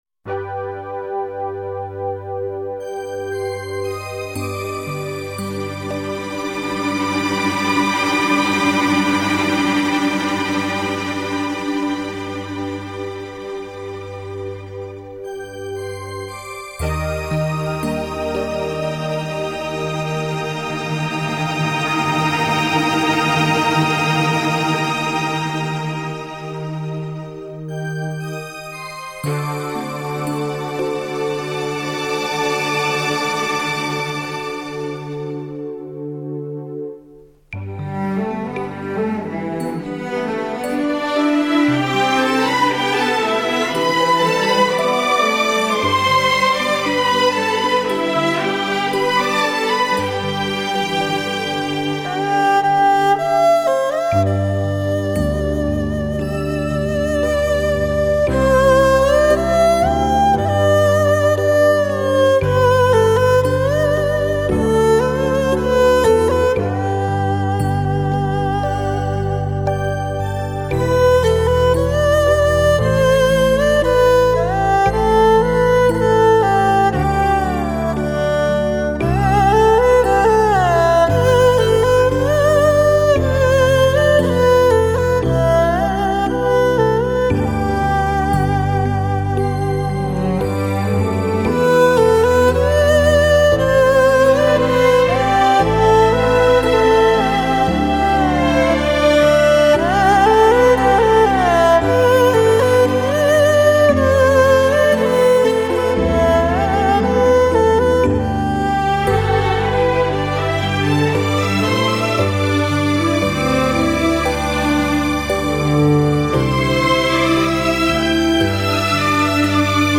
收录了最具民族色彩的乐器演绎出的音乐